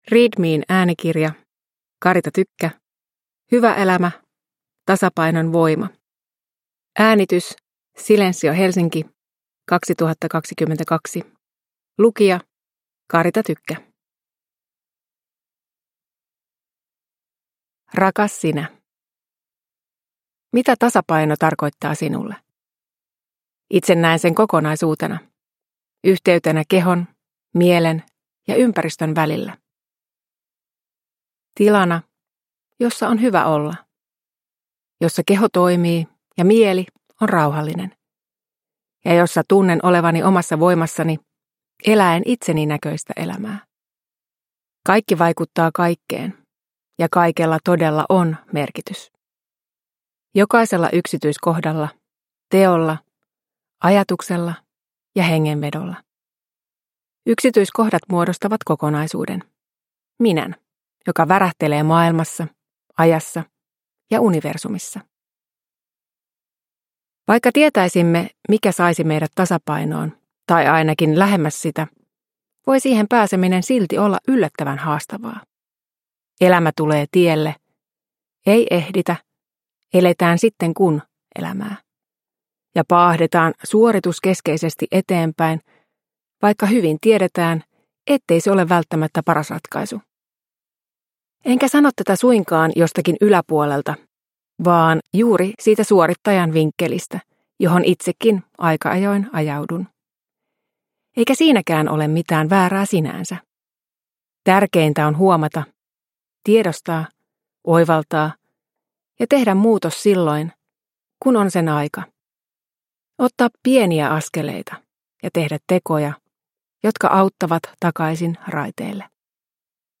Hyvä elämä - Tasapainon voima – Ljudbok